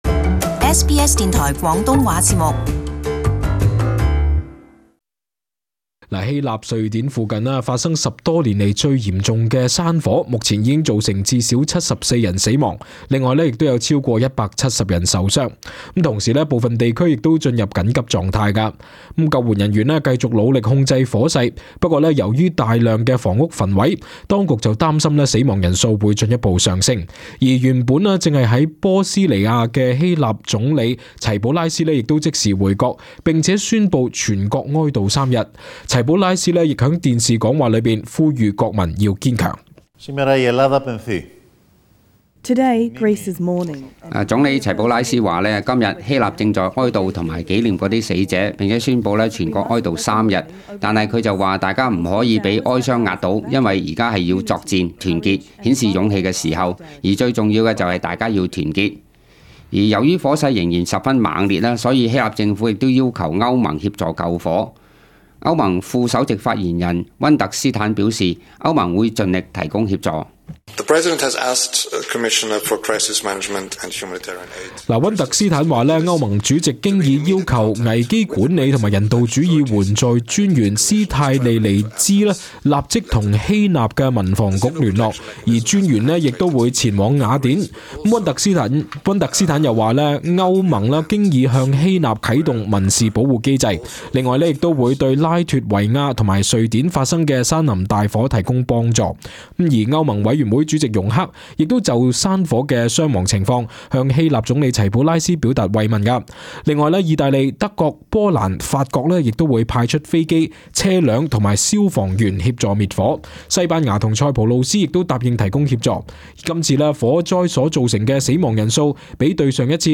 【時事報導】希臘雅典附近嚴重山火,死亡人數最少超過74人